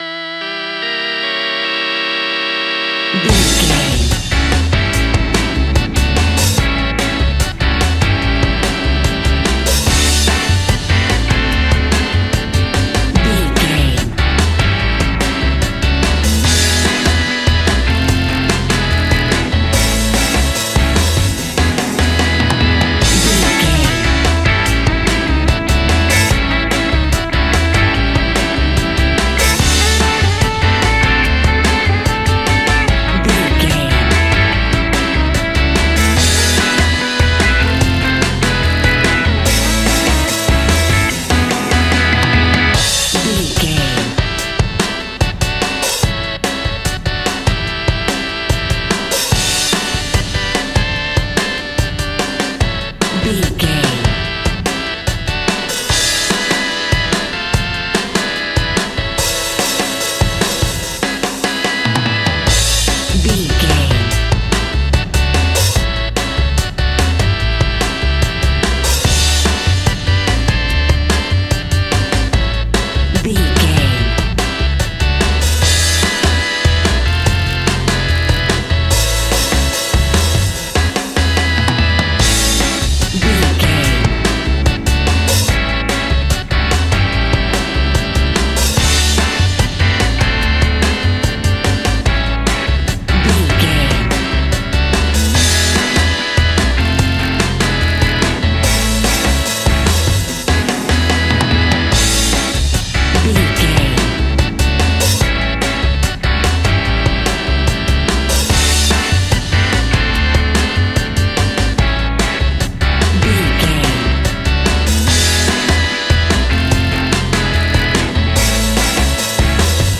Ionian/Major
80s
pop rock
indie pop
energetic
uplifting
catchy
upbeat
acoustic guitar
electric guitar
drums
piano
organ
electric piano
bass guitar